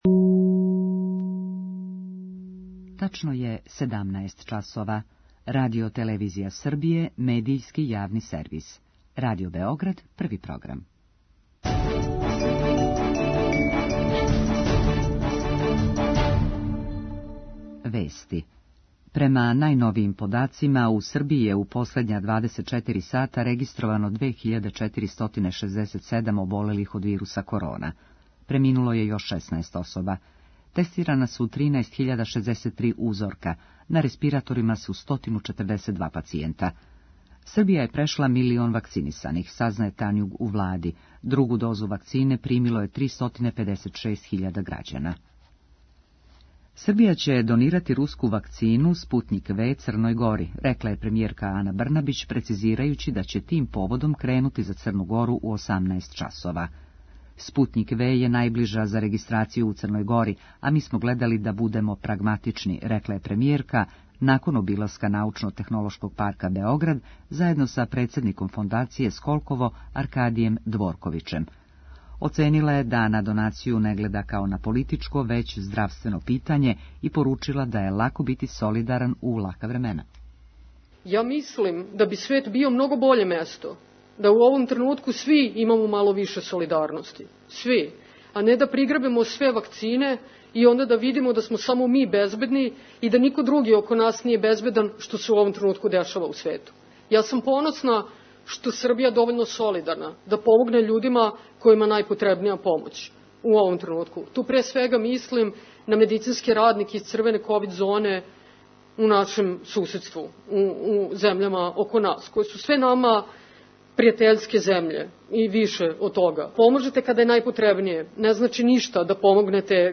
Шта представља социјална карта и које све податке садржи? Гошћа емисије је проф.др Дарија Кисић Тепавчевић, министарка за рад, запошљавање, борачка и социјална питања.